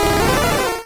Cri de Rapasdepic dans Pokémon Rouge et Bleu.